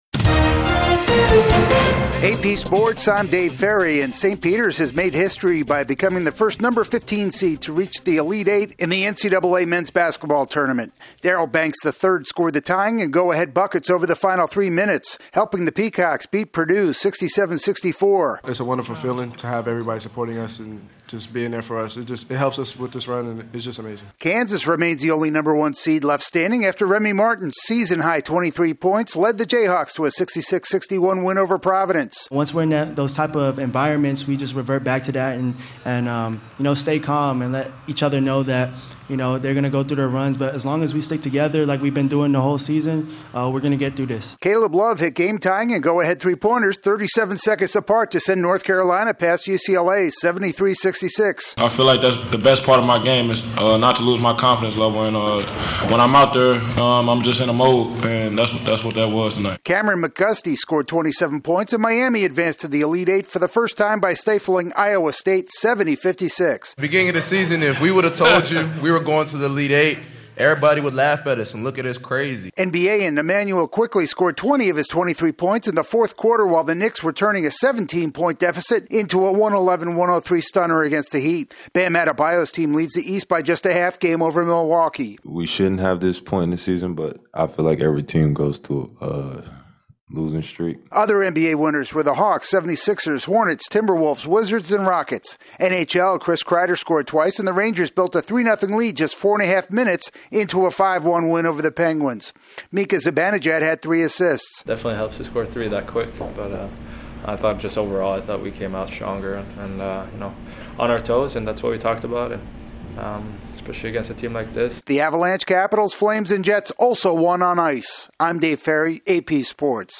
Update on the latest sports